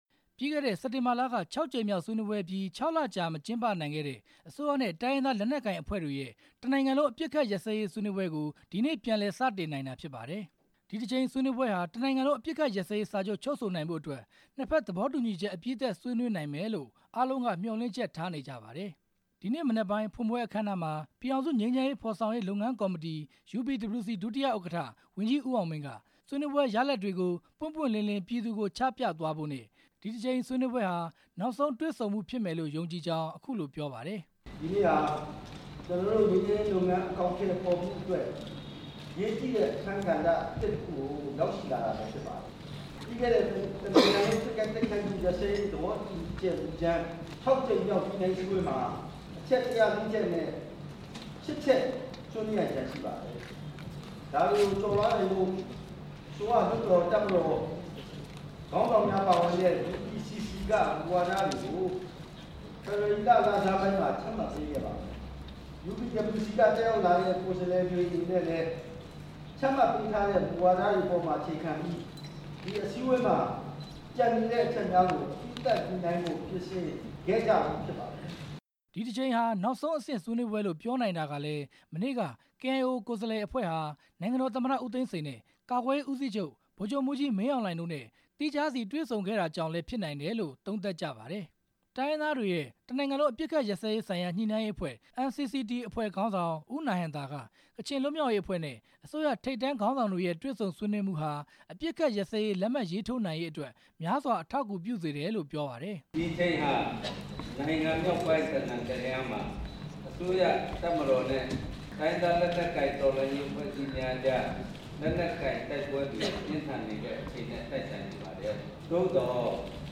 UPWC နဲ့NCCT တို့ ၇ ကြိမ်မြောက် တွေ့ဆုံဆွေးနွေးပွဲ အကြောင်းတင်ပြချက်